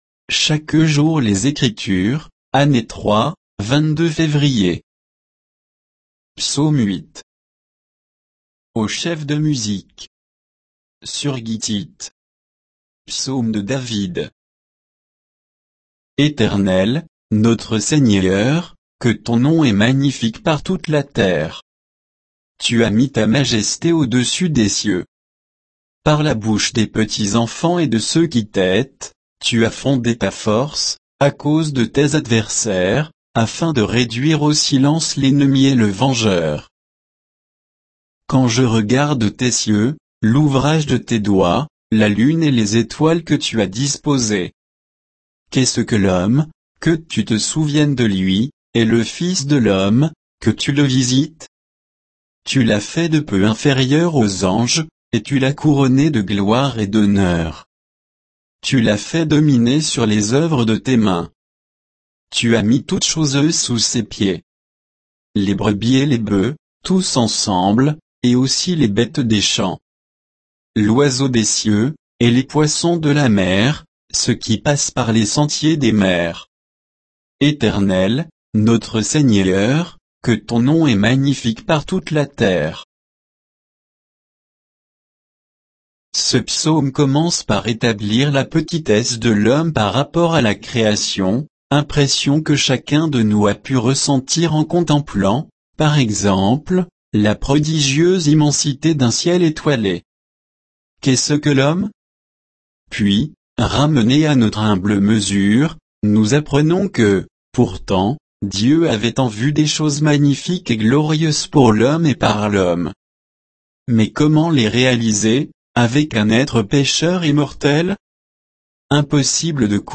Méditation quoditienne de Chaque jour les Écritures sur Psaume 8